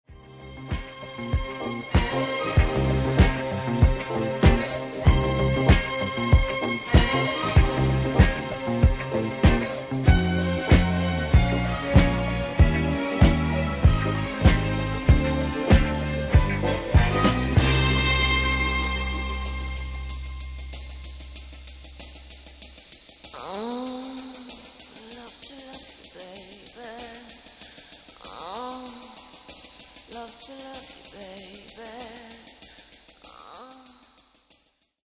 Genere:   Disco Soul